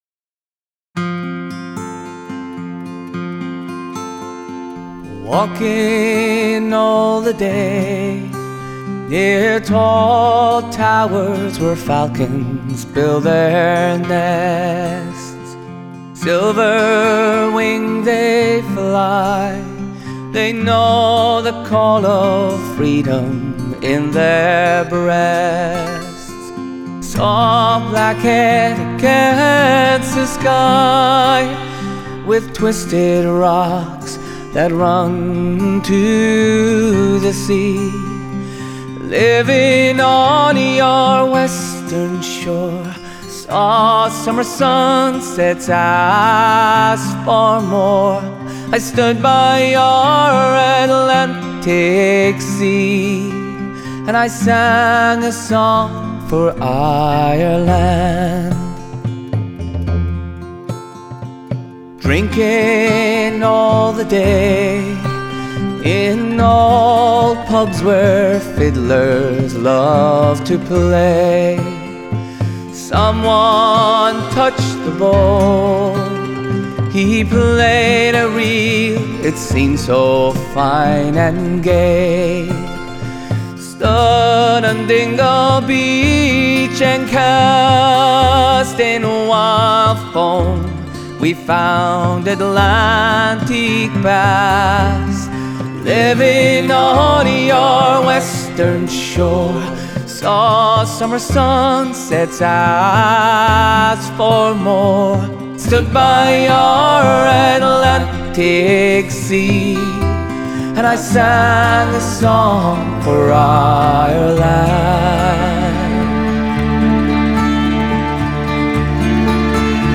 Genre: Folk/Country/Pop